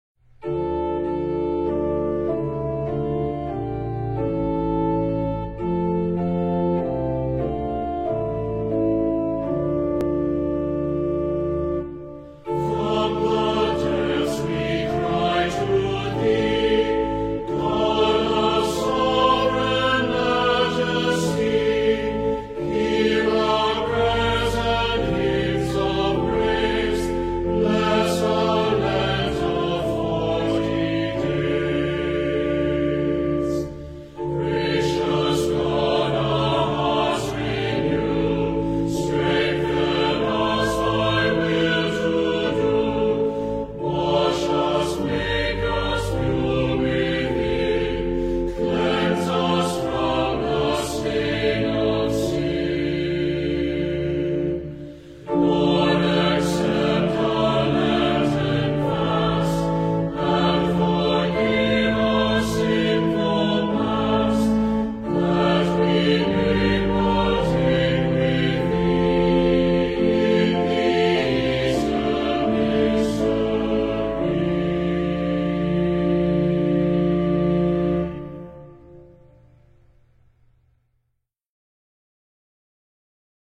HYMN  From the Depths We Cry to Thee           Music: attrib.
From-the-Depths-We-Cry-to-Thee-hymn.mp3